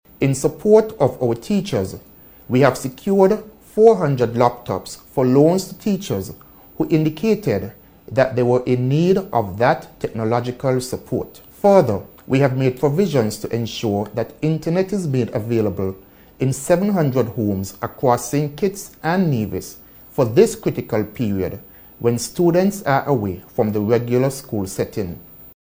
That’s Minister of Education, the Hon. Shawn Richards.
Minister Richards made the remarks in a recent address to St. Kitts and Nevis, as we continue to fight against the Coronavirus.